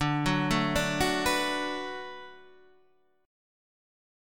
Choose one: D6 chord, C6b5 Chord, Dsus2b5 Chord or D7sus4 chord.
D6 chord